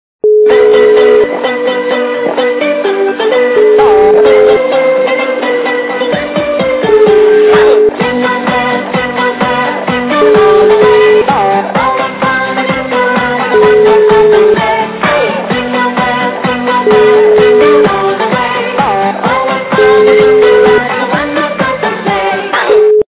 Новогодние